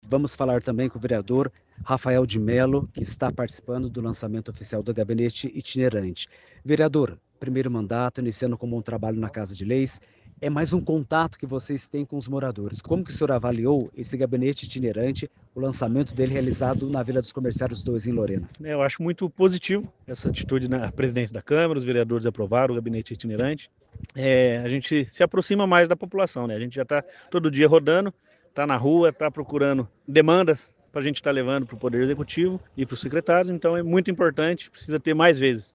Áudio do vereador Rafael de Melo (MDB);